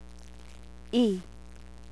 chirik chaser "i" as in kilo